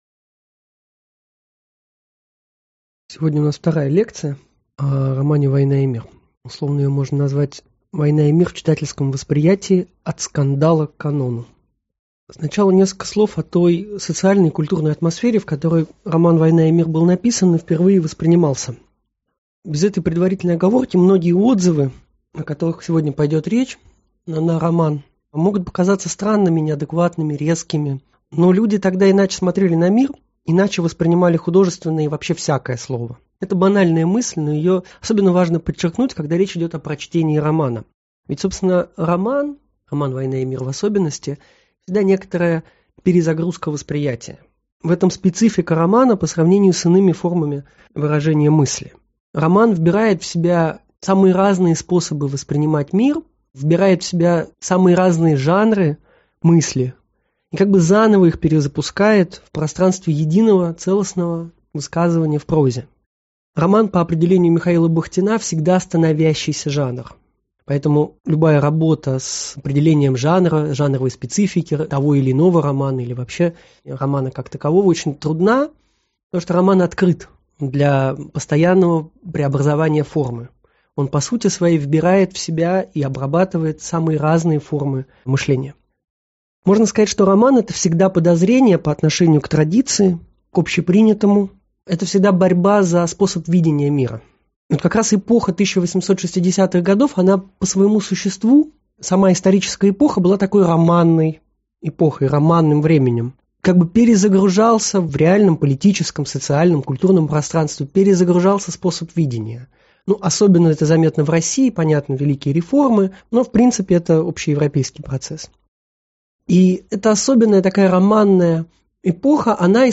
Аудиокнига От скандала к канону.